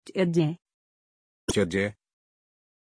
Pronunciation of Teddie
pronunciation-teddie-ru.mp3